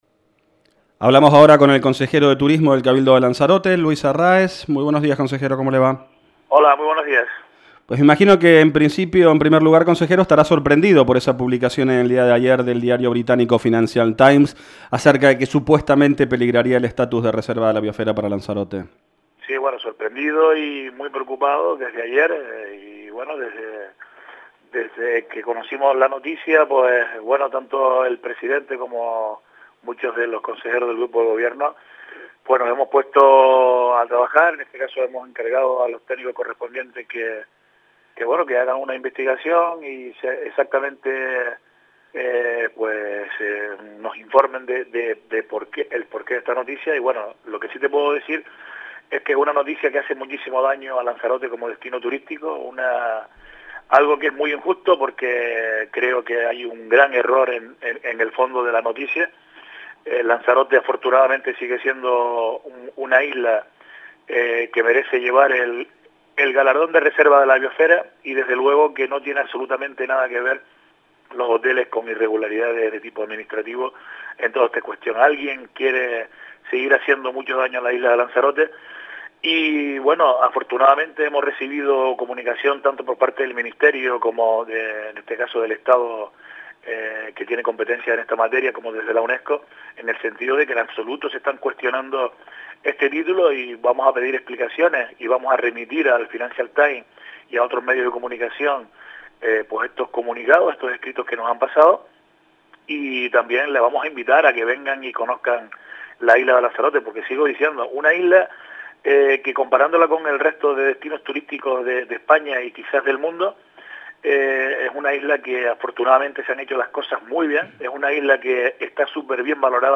“El tema hotelero, aunque parezca difícil, porque hay muchos intereses políticos detrás, debería resolverse. Será conveniente que todos hiciéramos un esfuerzo para resolverlo cuanto antes”, sostuvo el consejero de Turismo del Cabildo, Luis Arráez, en declaraciones realizadas este miércoles a Lancelot Digital.